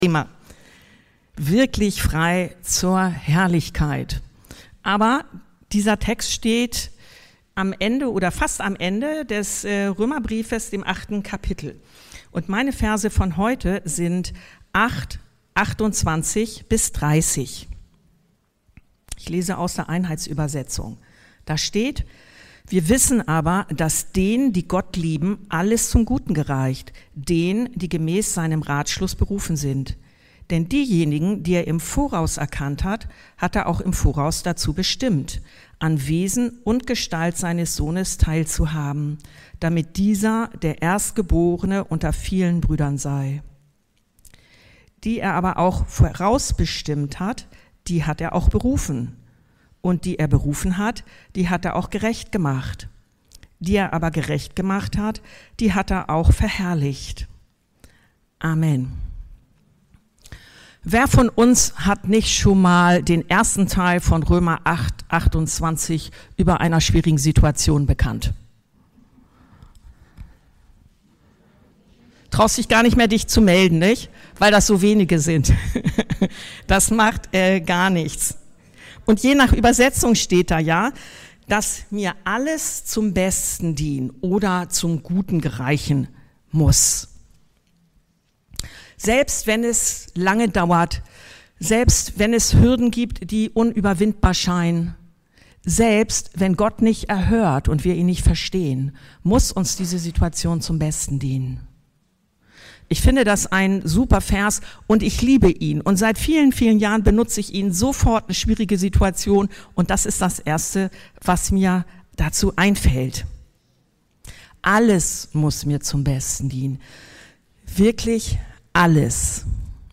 Wirklich frei zur Herrlichkeit ~ Anskar-Kirche Hamburg- Predigten Podcast